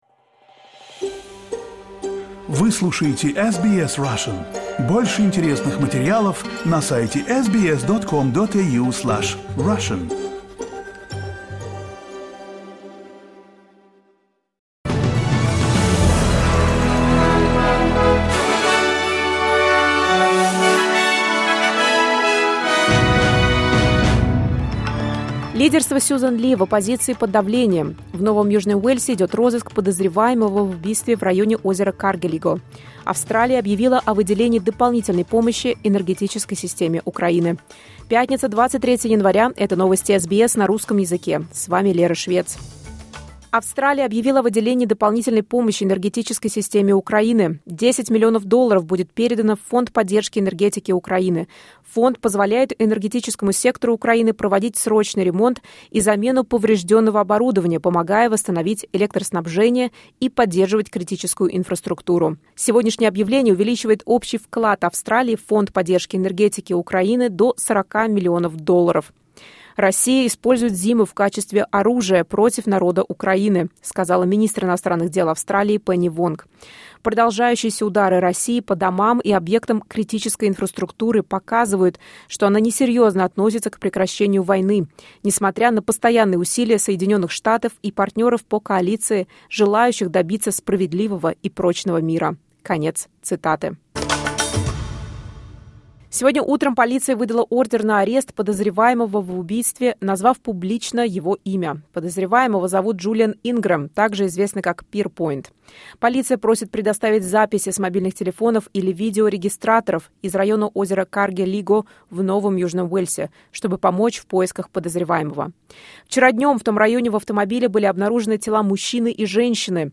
Новости SBS на русском языке — 23.01.2026